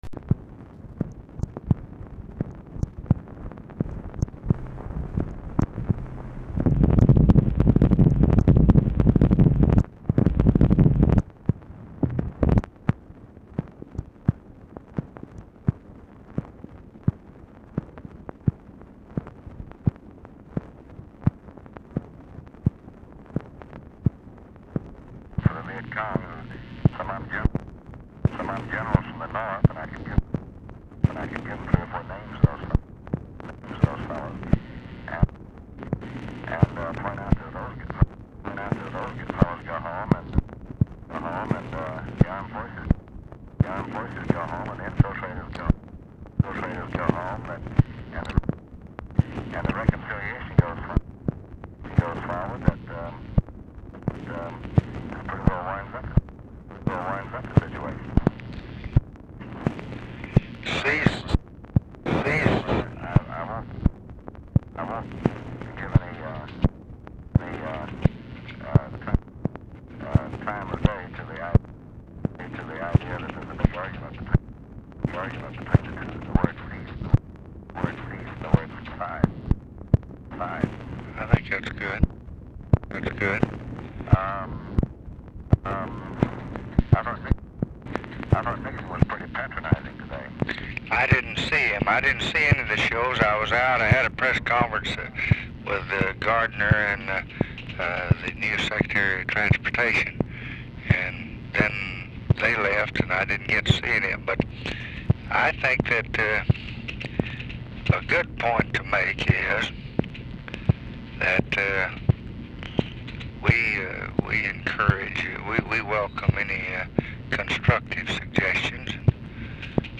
0:26 MACHINE NOISE PRECEDES CALL; RECORDING STARTS AFTER CONVERSATION HAS BEGUN; POOR SOUND QUALITY
Format Dictation belt
Location Of Speaker 1 LBJ Ranch, near Stonewall, Texas
Specific Item Type Telephone conversation